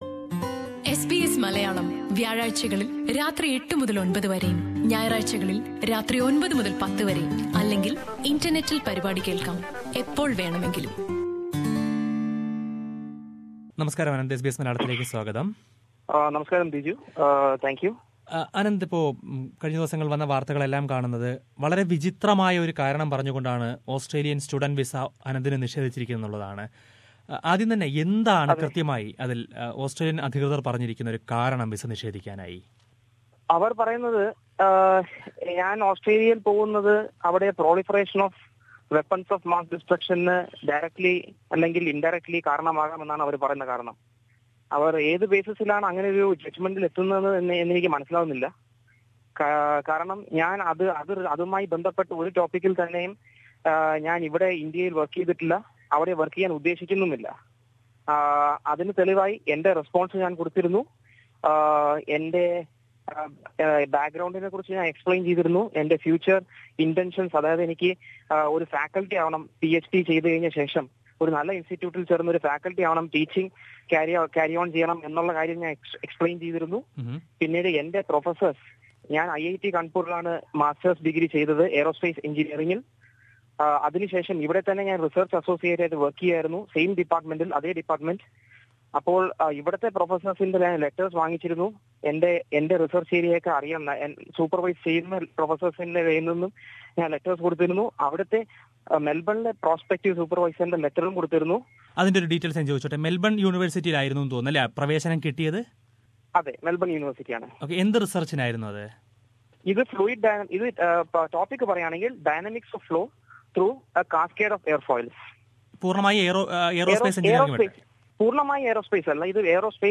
അഭിമുഖം...